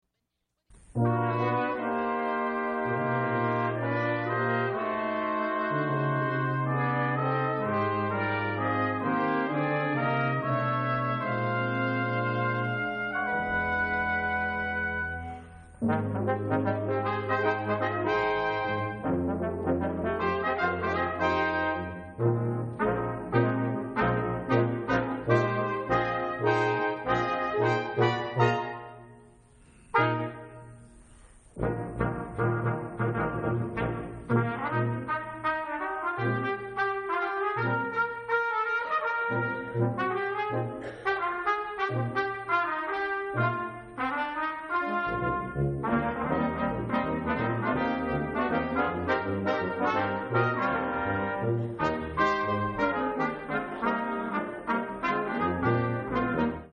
Seton Performance Series - 1/18/2004